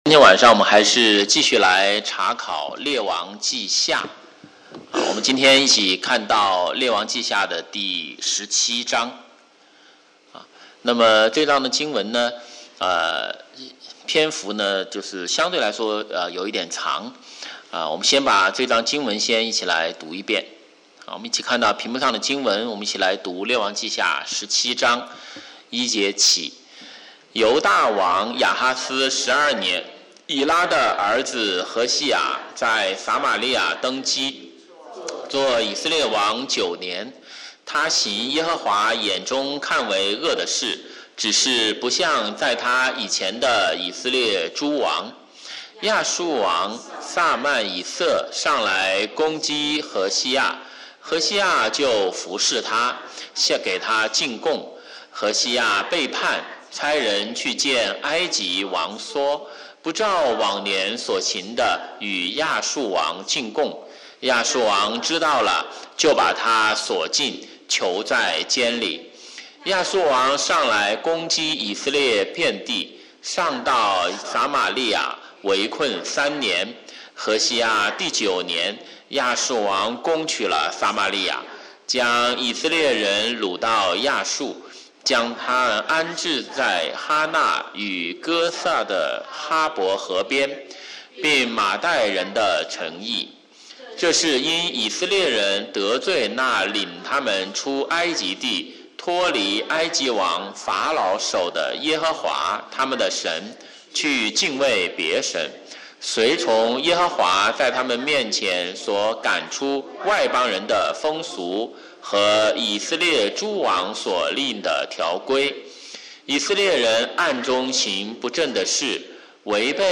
週五晚上查經講道錄音